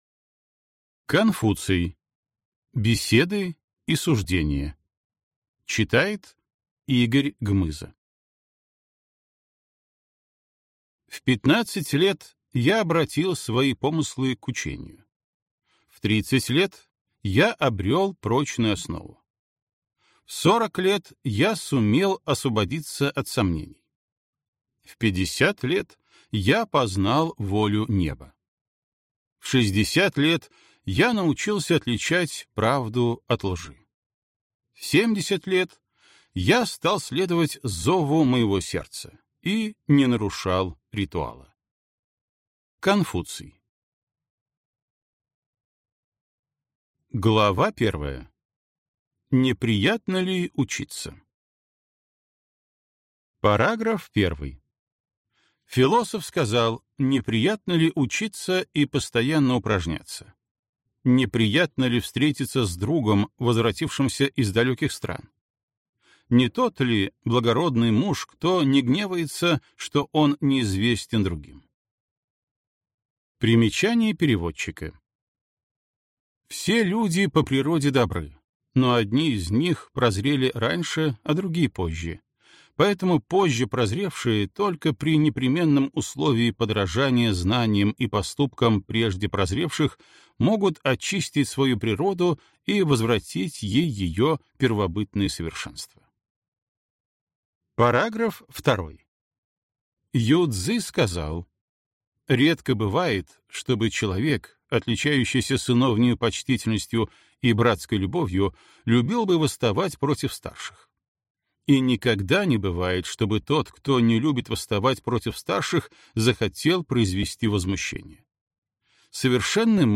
Аудиокнига Беседы и суждения | Библиотека аудиокниг